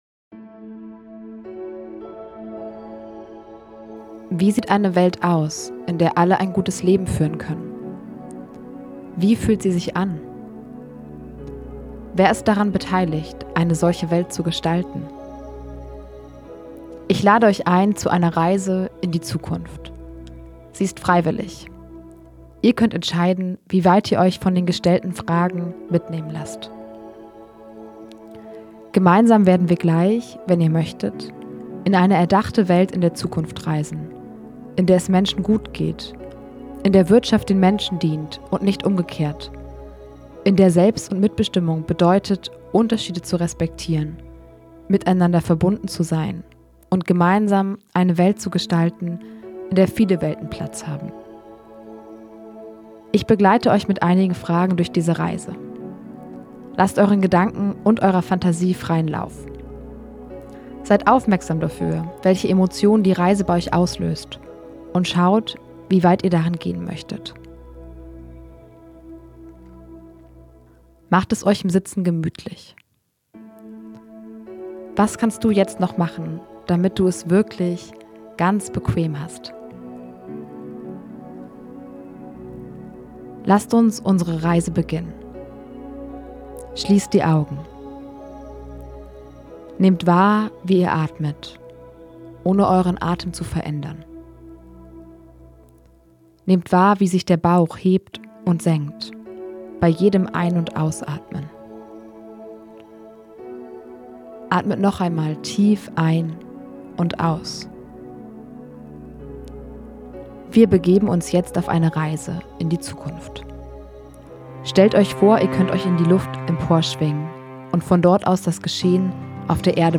Traumreise-mit-Musik-1.mp3